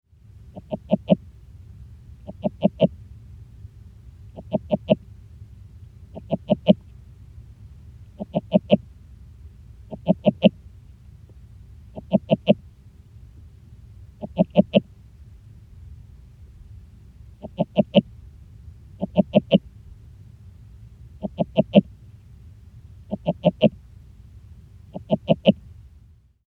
All sounds were recorded with a hydrophone, which is an underwater microphone.
Sound  This is a 26 second continuous underwater recording of the advertisement calls of a single frog - shown above on the left on the bottom.